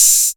Tuned hi hats Free sound effects and audio clips
• Dark Open Hi Hat Sound A Key 30.wav
Royality free open hat sound tuned to the A note. Loudest frequency: 7570Hz
dark-open-hi-hat-sound-a-key-30-3VD.wav